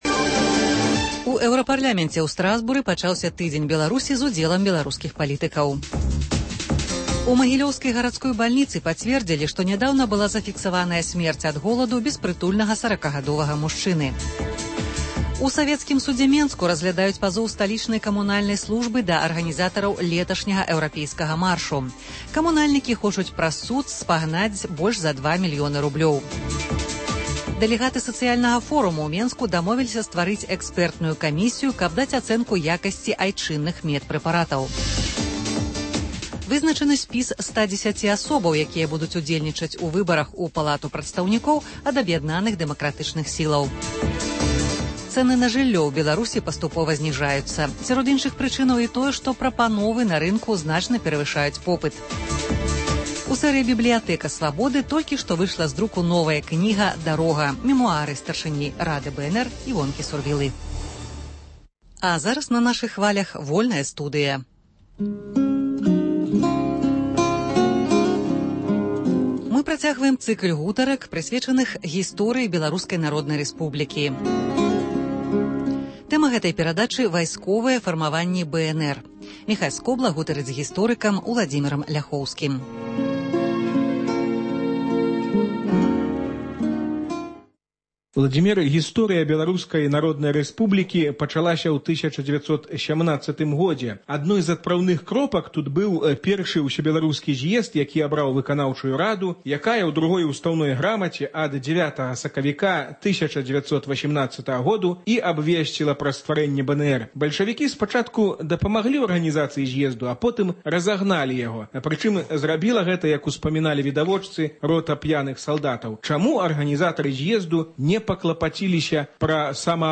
Гутарка з гісторыкам